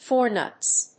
アクセントfor núts